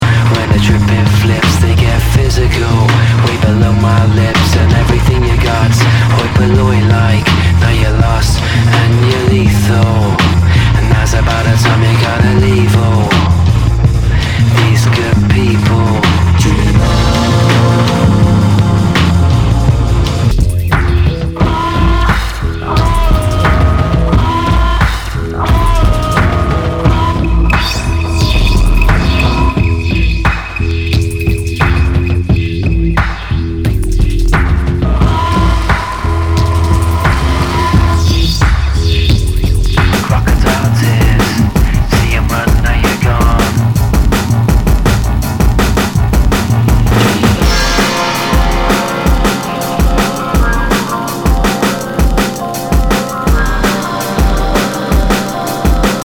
Nu- Jazz/BREAK BEATS